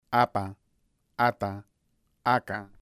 Secuencias que demuestran los distintos lugares de articulación de las consonantes oclusivas.